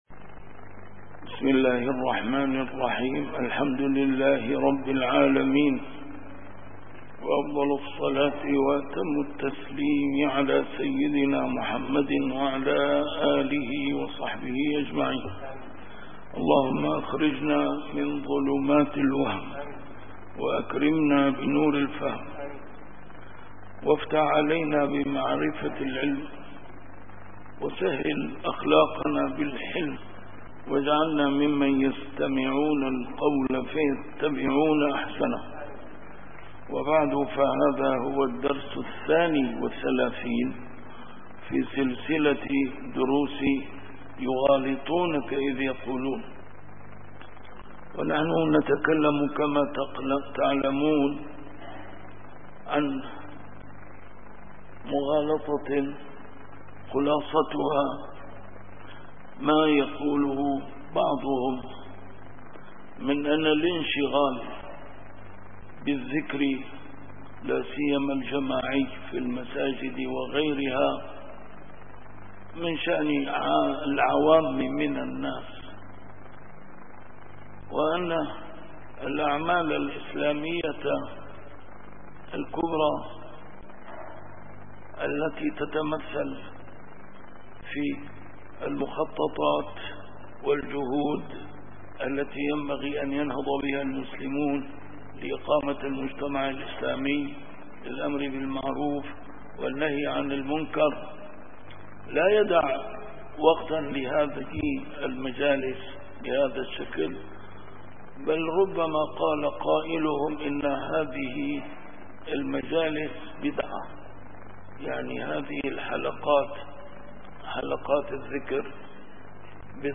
A MARTYR SCHOLAR: IMAM MUHAMMAD SAEED RAMADAN AL-BOUTI - الدروس العلمية - يغالطونك إذ يقولون - 32- يغالطونك إذ يقولون: مجالس الذكر تورُّطٌ في البدعة وملهاة عن العمل الإسلامي